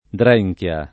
[ dr $j k L a ]